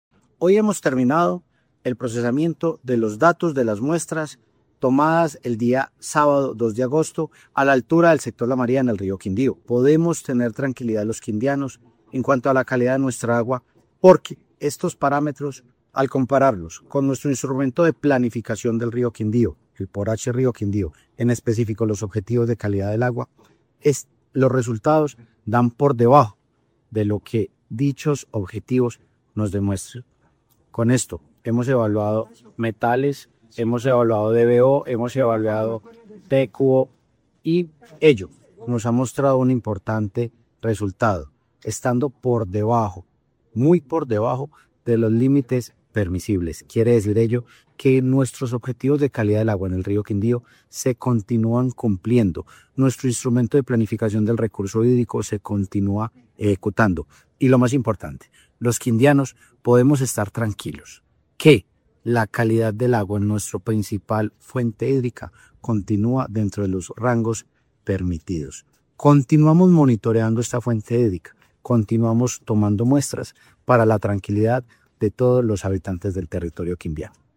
Director encargado CRQ, Juan Esteban Cortés